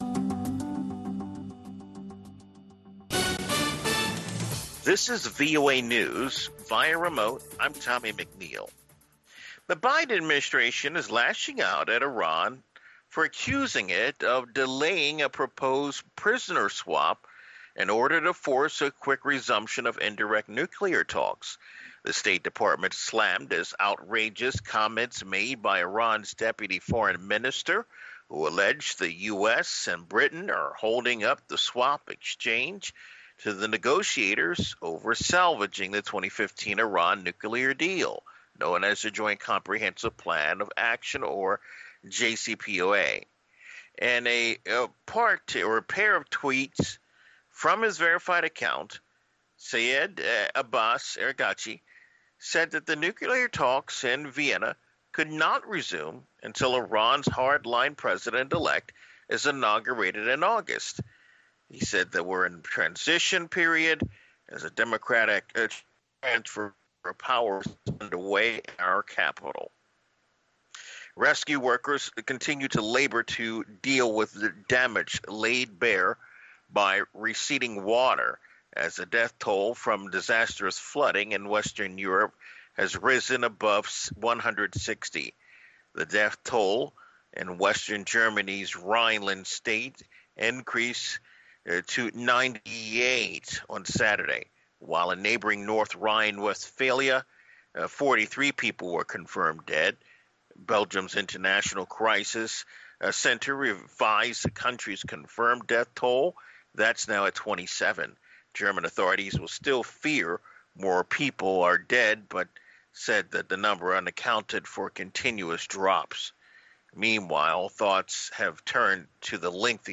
VOA Newscasts
We bring you reports from our correspondents and interviews with newsmakers from across the world.